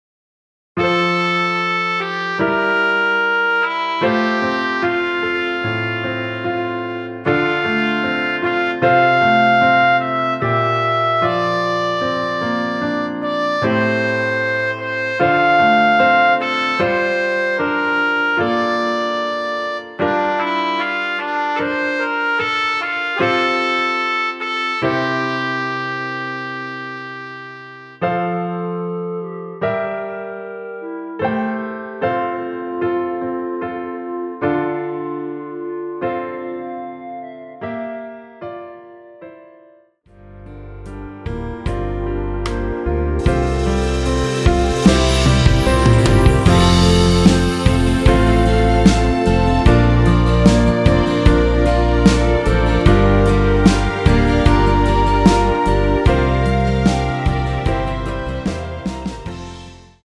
발매일 1995.05 원키에서(+5)올린 멜로디 포함된 MR 입니다.(미리듣기 참조)
앞부분30초, 뒷부분30초씩 편집해서 올려 드리고 있습니다.
중간에 음이 끈어지고 다시 나오는 이유는